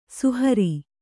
♪ suhari